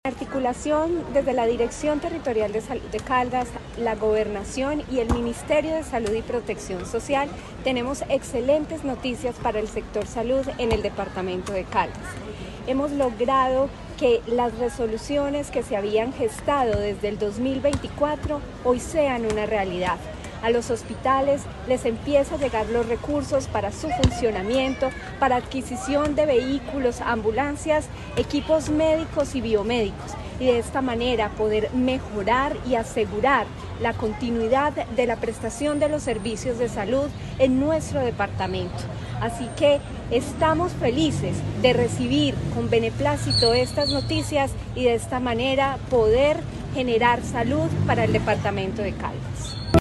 Natalia Castaño Díaz, directora de la DTSC.
AUDIO-NATALIA-CASTANO-DIAZ-DIRECTORA-DTSC-1.mp3